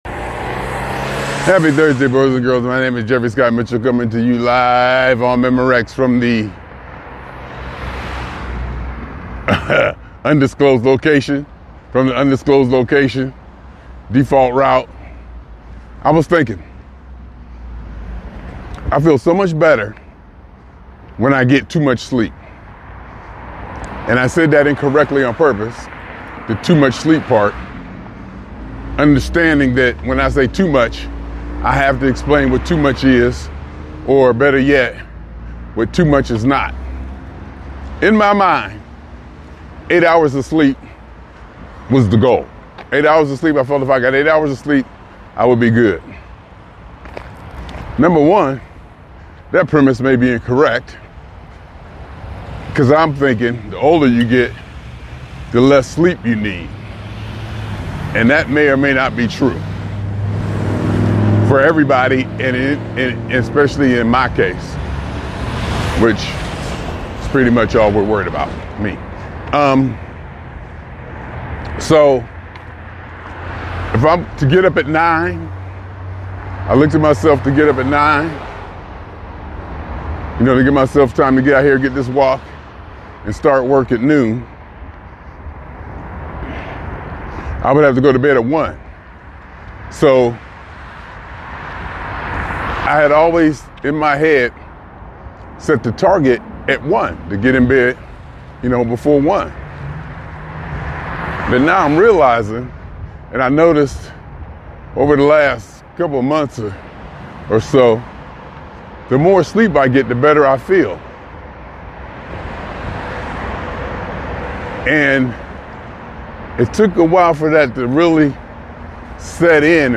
In a reflective talk